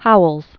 (houəlz), William Dean 1837-1920.